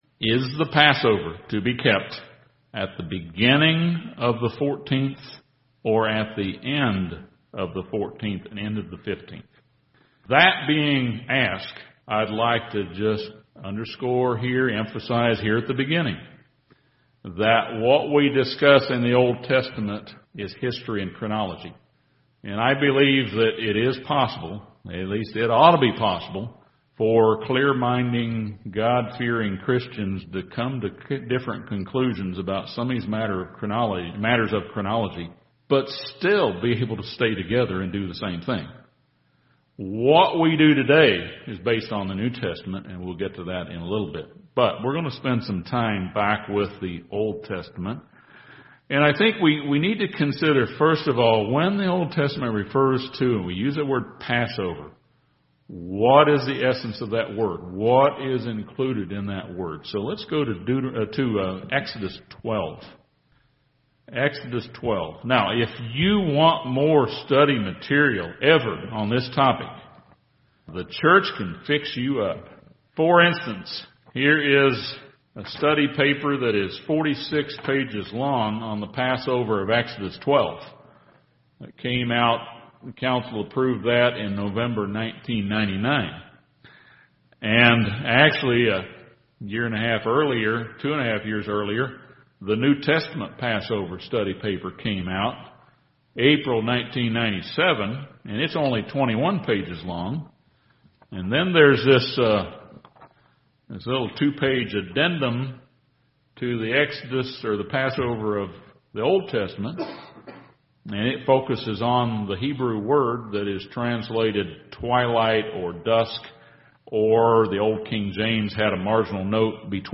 This sermon discusses the chronology of the Passover of Exodus 12 and why we keep the the Passover at the beginning of the 14th of Nisan. This is based upon what we find in the New Testament example of Christ and instructions of Paul.